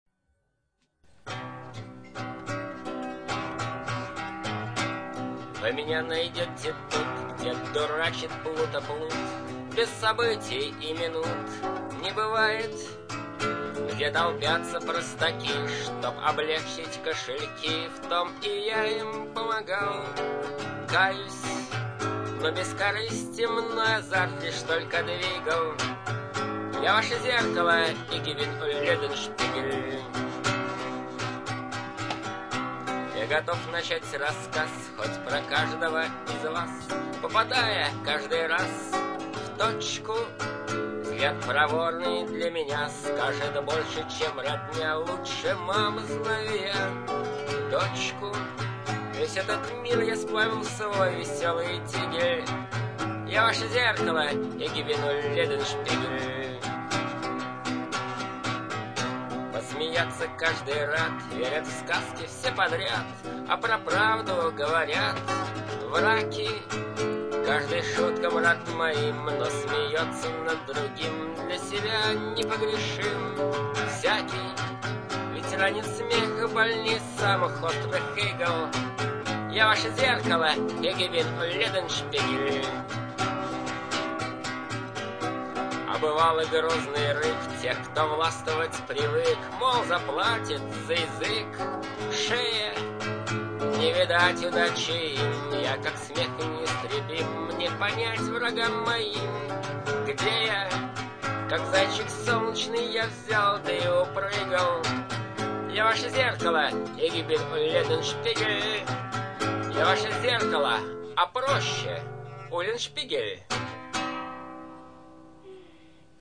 иcполнение (голоc, гитаpа)
22 кГц 16 бит стерео